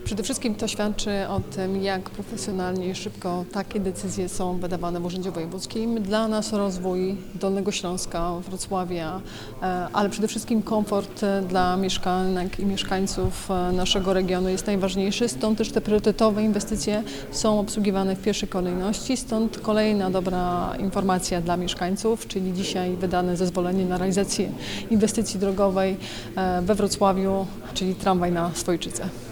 na-strone_1_wojewoda-o-zrid.mp3